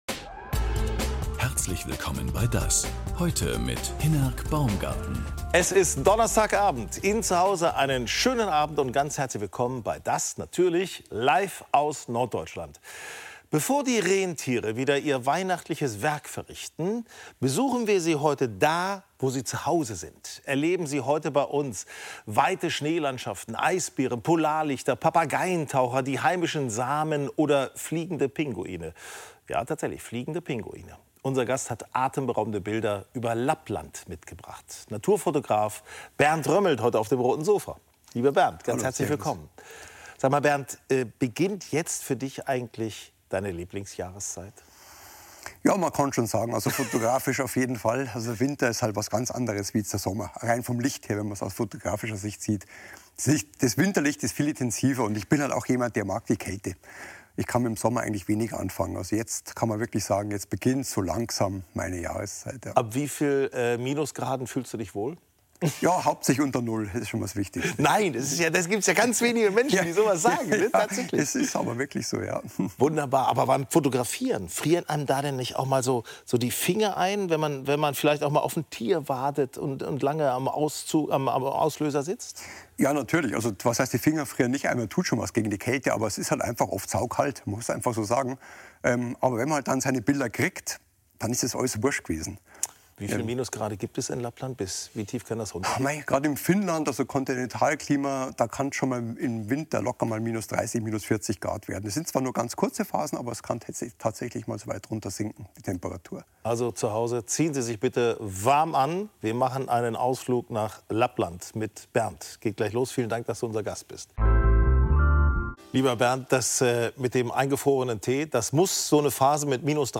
DAS! ist bekannt für intensive Interviews mit prominenten und kompetenten Gästen auf dem Roten Sofa. Die Gesprächsfassung (ohne Filmbeiträge) vom Vortag gibt es auch als Audio-Podcast.